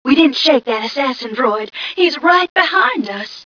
1 channel
mission_voice_m1ca044.wav